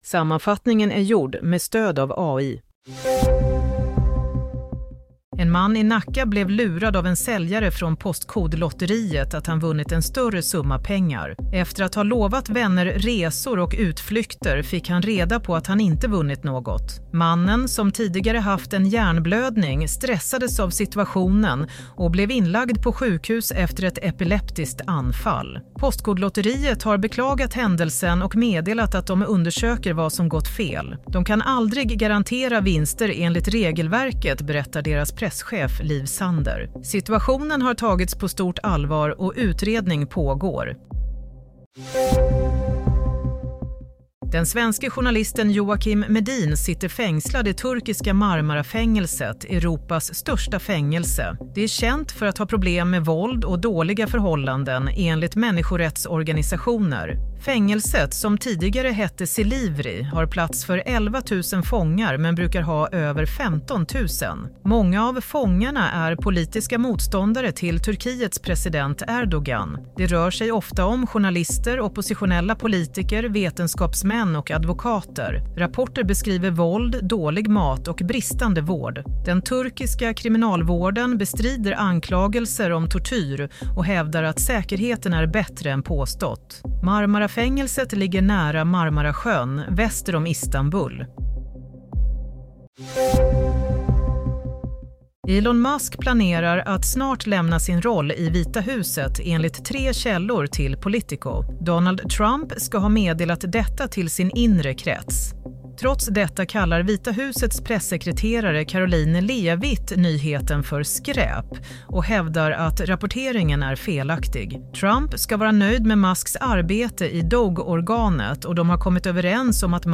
Play - Nyhetssammanfattning – 2 april 22:00